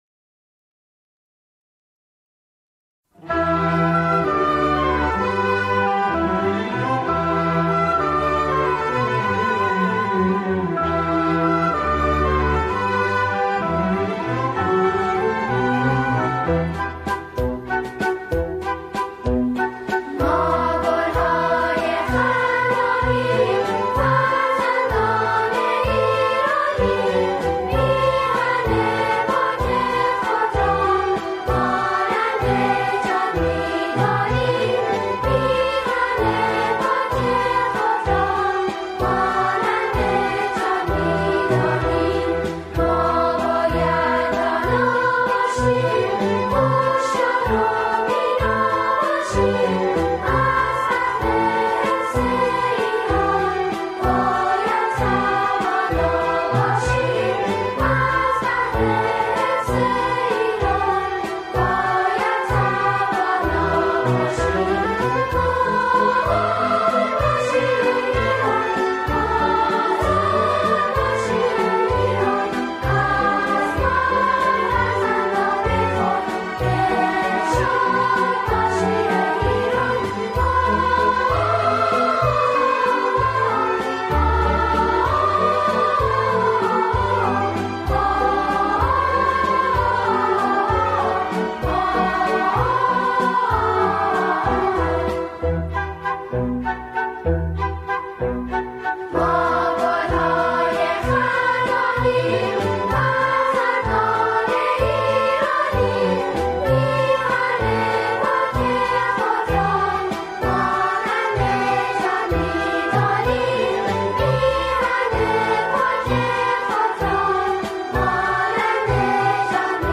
با اجرای گروهی از جمعخوانان
جمعخوانان، این قطعه را با شعری درباره ایران اجرا می‌کنند.